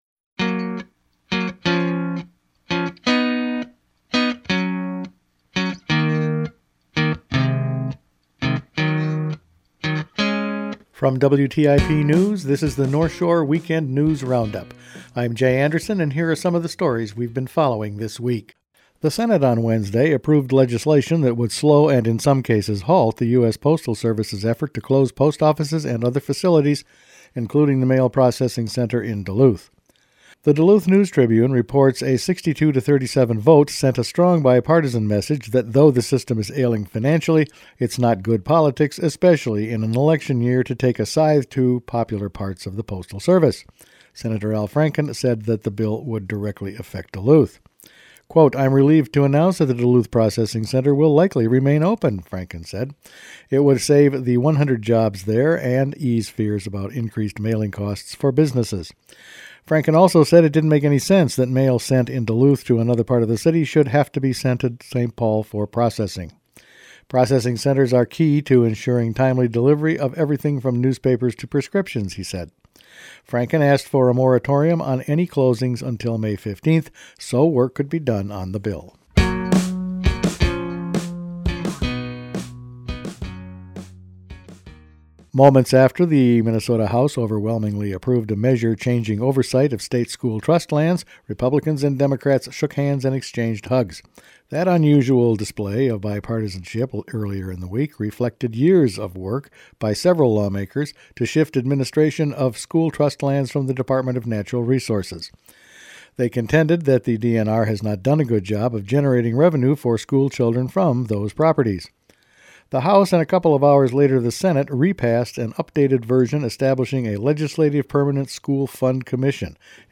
Each weekend WTIP news produces a round up of the news stories they’ve been following this week. There’s a temporary hold placed on Post Office closings, Northern school trust lands administration may change, action on BWCAW haze from MPCA, Cold War era barrels dumped in Lake Superior, more on Asian carp and a hike in hunting and fishing fees…all in this week’s news.